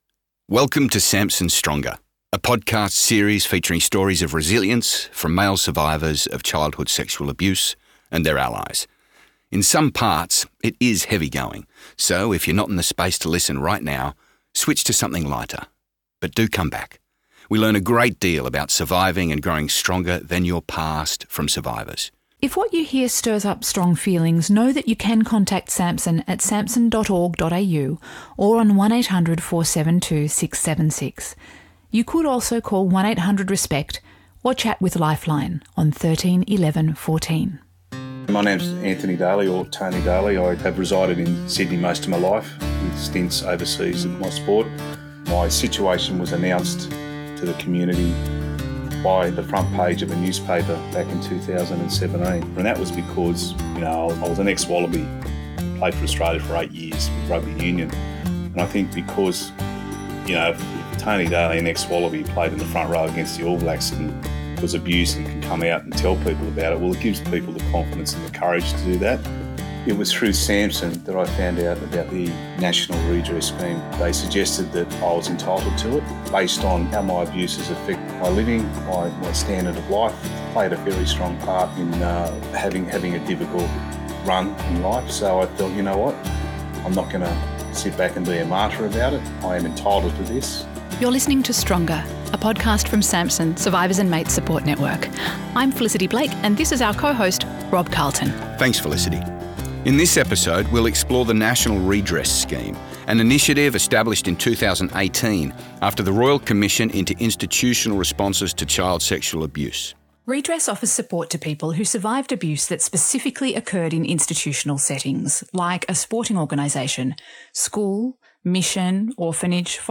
This ‘How-To’ explainer features a personal story from a survivor who sought redress through the National Redress Scheme. We'll also hear reflections from legal experts, a redress support service worker, and a counsellor. This episode is specifically designed to help potential applicants understand the scheme, its benefits, how to access it, and what they might be able to expect from the experience.